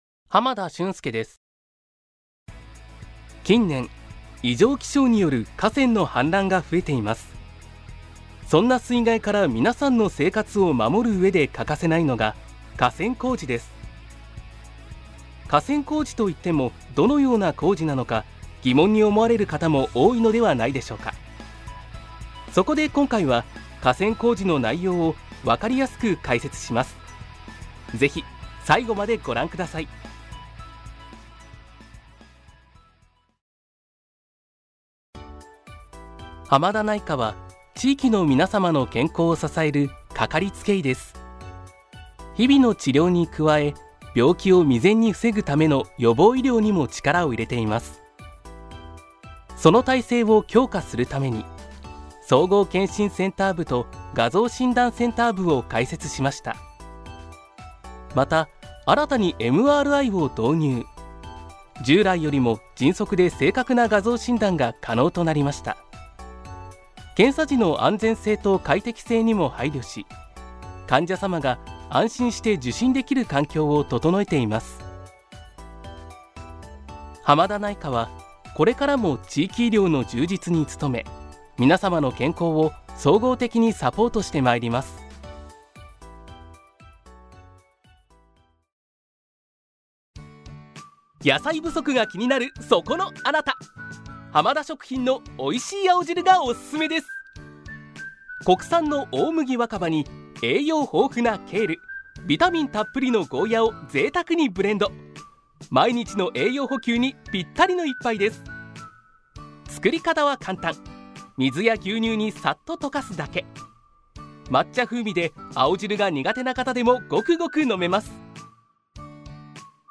ボイスサンプル
• 飛び出す爽やかボイス
• 音域：高～中音
• 声の特徴：明るい、さわやか
真っ直ぐ元気な声のナレーションが特徴です。